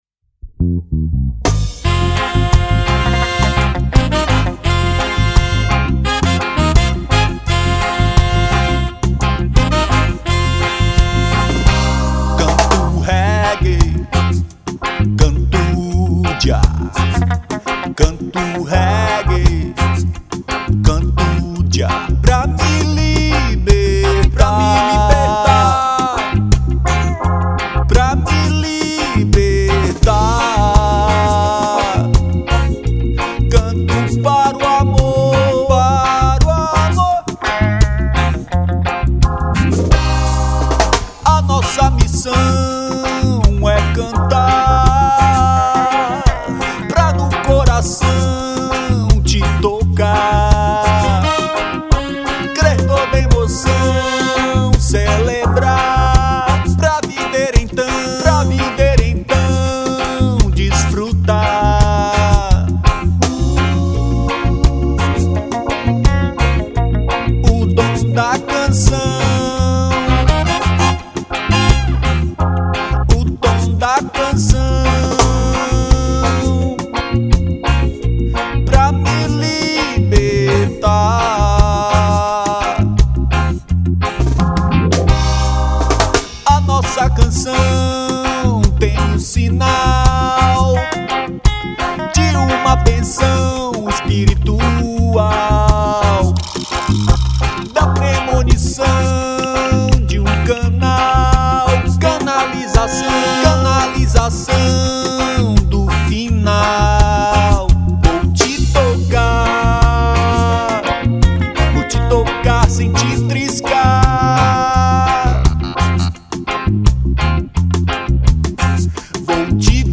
933   05:14:00   Faixa:     Reggae